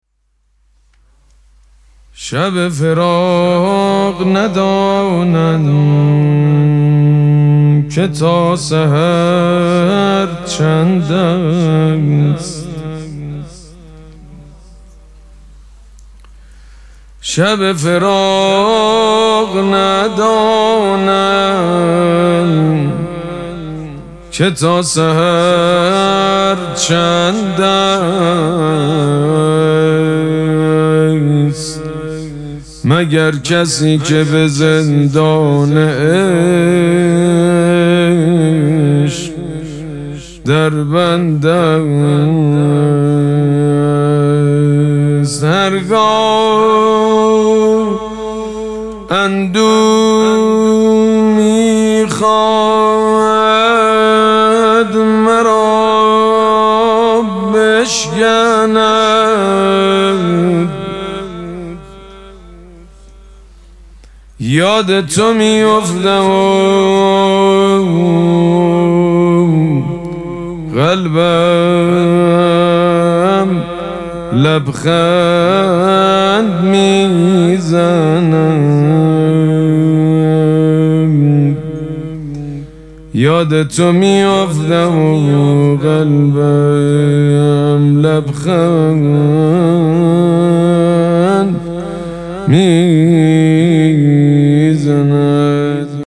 مراسم مناجات شب هفتم ماه مبارک رمضان
شعر خوانی